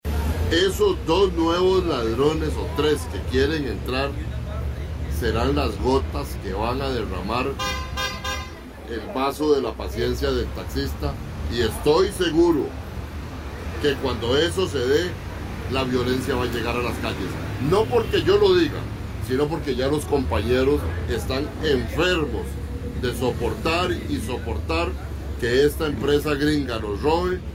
AUDIO-TAXISTA.mp3